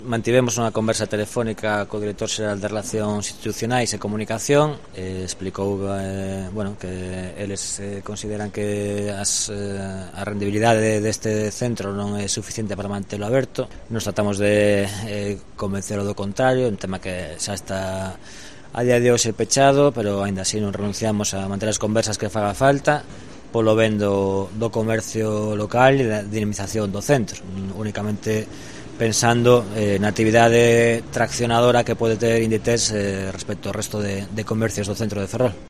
Jorge Suárez, alcalde de Ferrol, sobre el cierre de Zara.